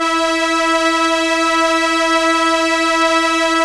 Index of /90_sSampleCDs/Keyboards of The 60's and 70's - CD1/STR_ARP Strings/STR_ARP Solina